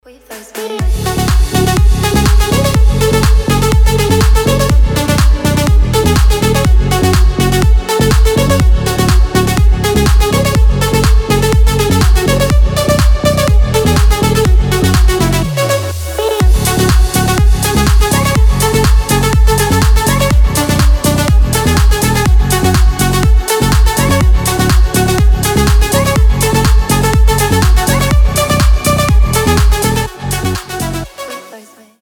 Забавная танцевальная песня